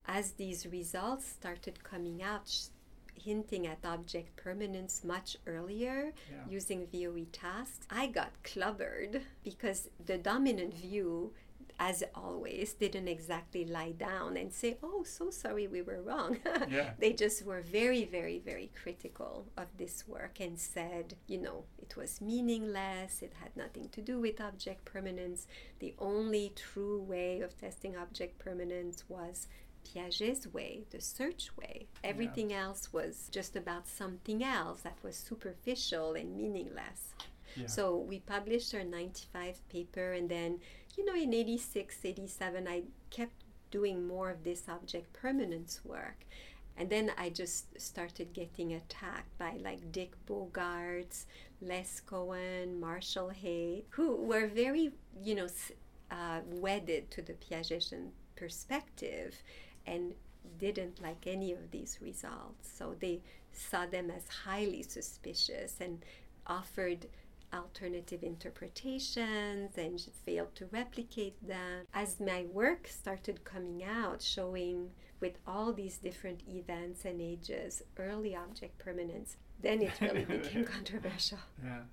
How controversial were these new ideas of infant cognition?  In this next excerpt,  Dr. Baillargeon describes the reactions she received: